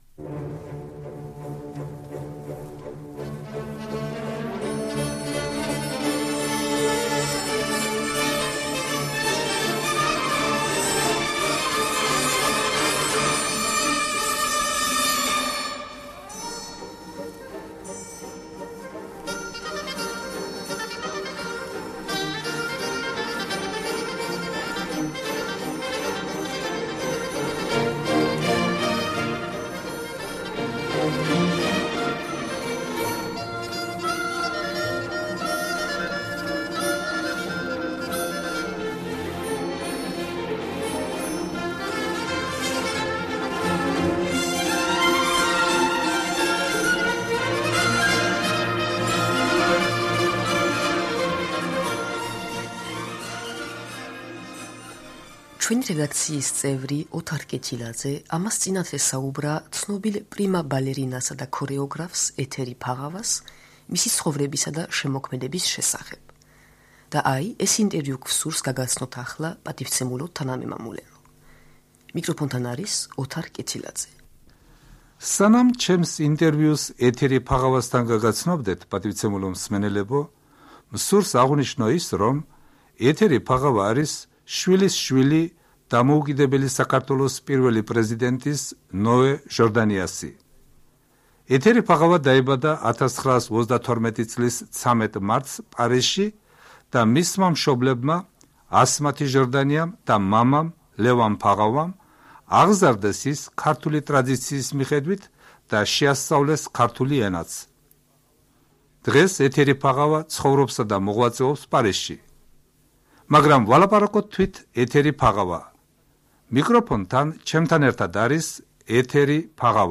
ინტერვიუ 1973 წლის 28 სექტემბერს გადაიცა რადიო თავისუფლების ეთერით.